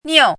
“拗”读音
niù
拗字注音：ㄠˇ/ㄠˋ/ㄋㄧㄡˋ
niù.mp3